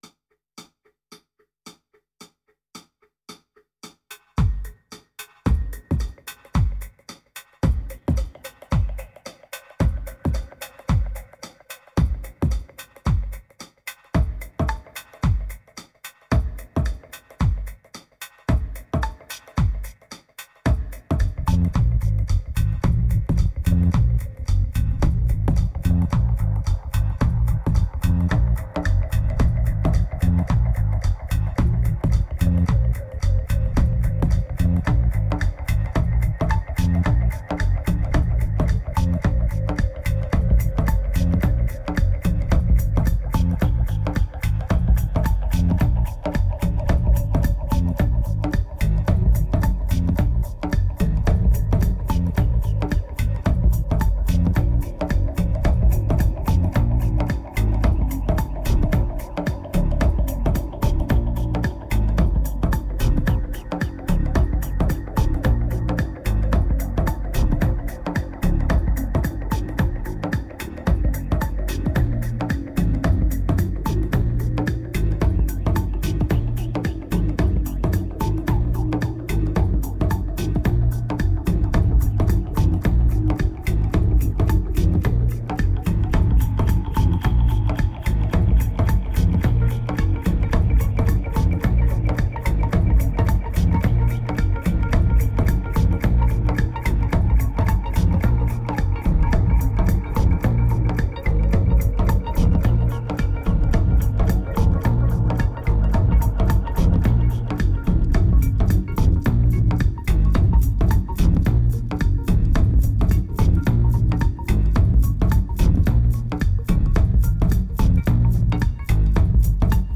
1849📈 - -14%🤔 - 111BPM🔊 - 2010-04-15📅 - -224🌟